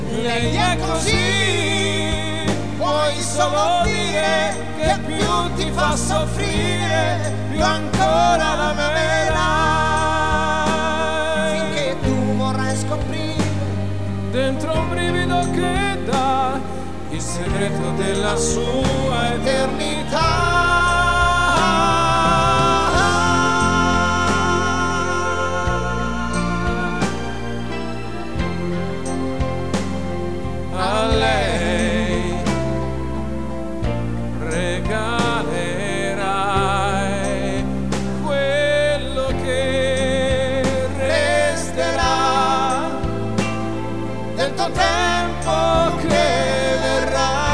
dueto